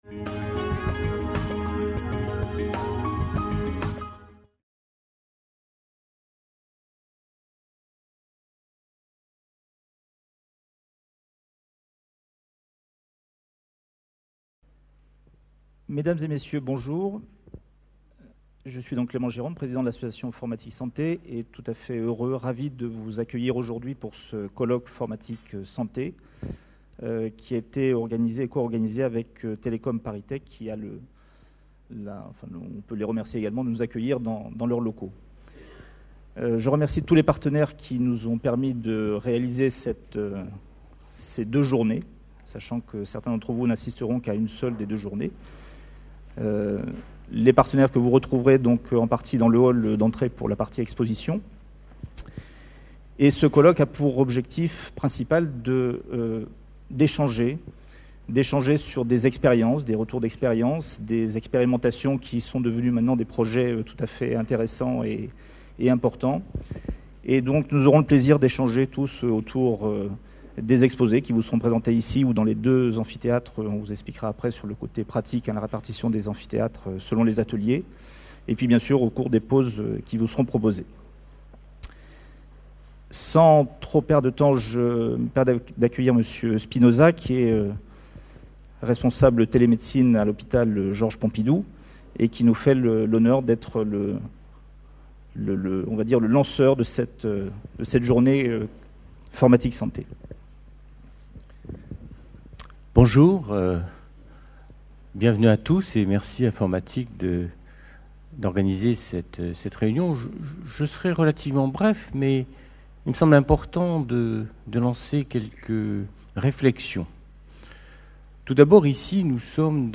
Conférence enregistrée lors du congrès international FORMATIC PARIS 2011.